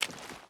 Water Walk 4.wav